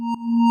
feat(call): add call end sound